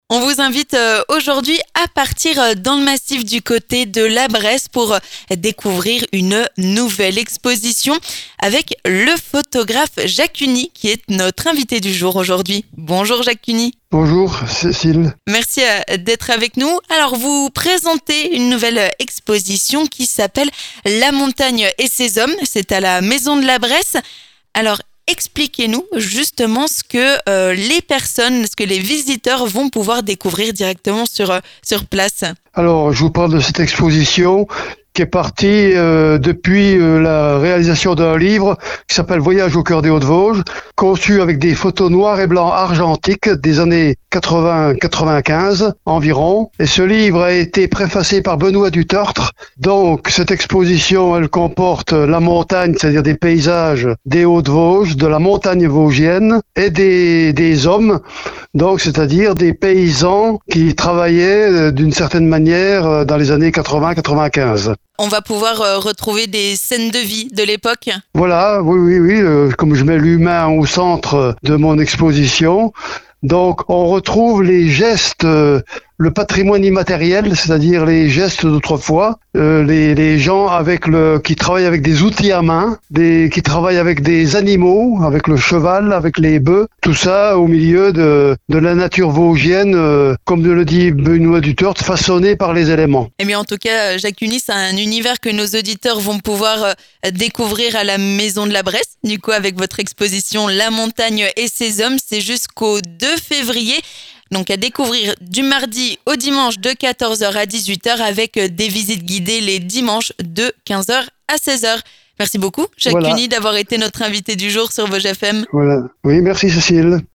3. L'invité du jour